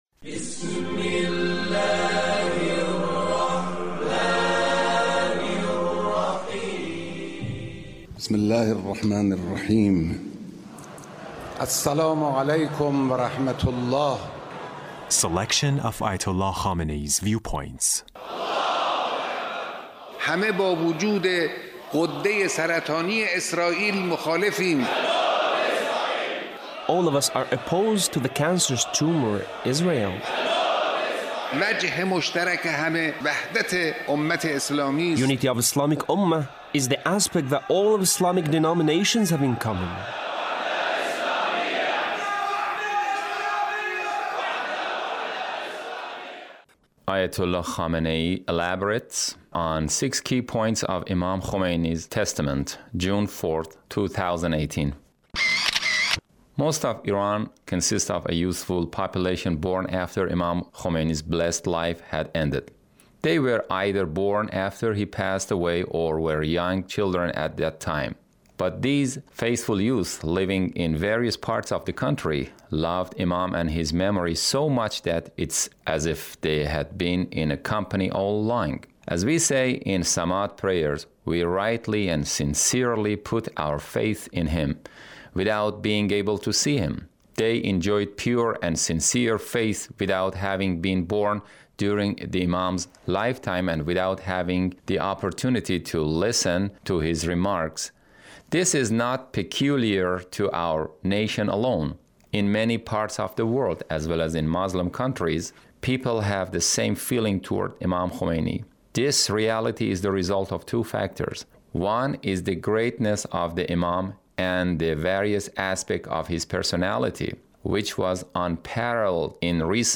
Leader's Speech (1724)